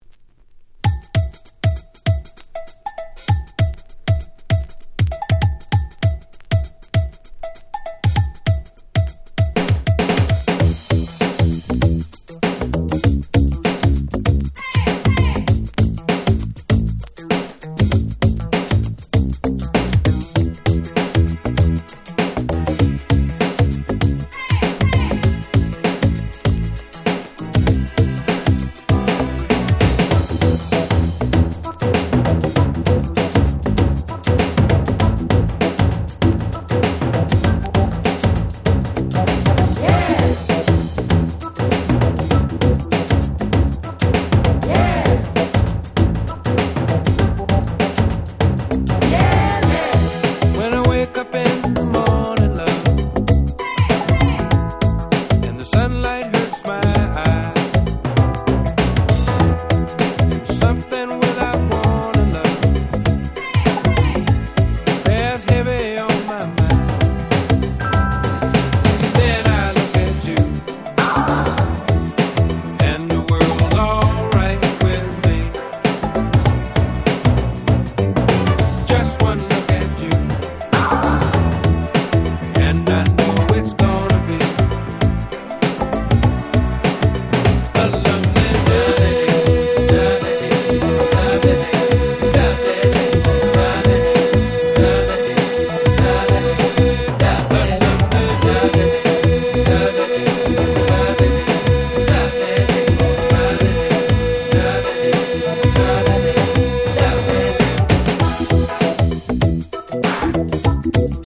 音質もバッチリ！！
Soul ソウル レアグルーヴ